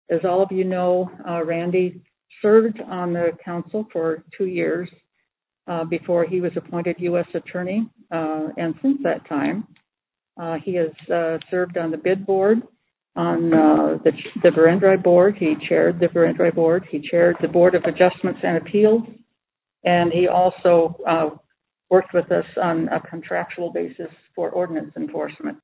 Fort Pierre mayor Gloria Hanson says Seiler has a variety of experience that will be beneficial to the council.